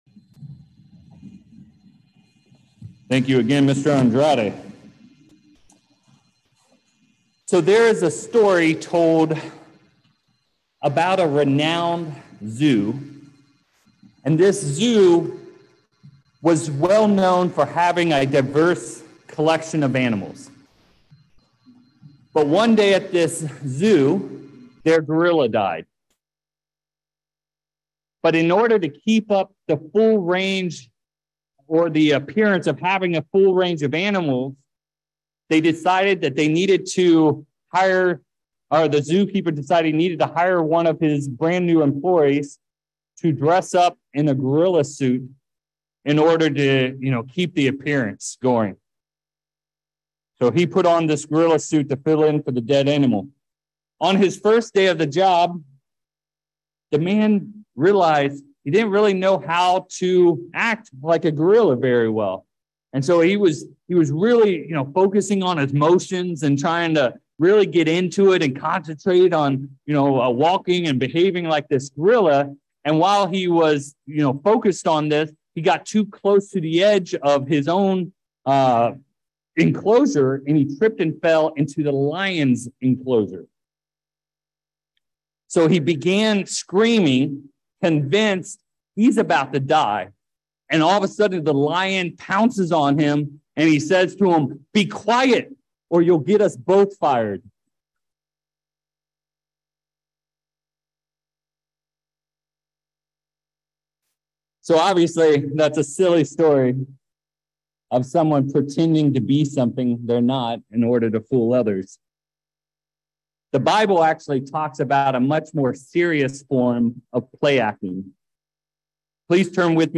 4/22/23 In this sermon, we dive into the topic of labeling someone a hypocrite and what actions are seen as containing hypocrisy.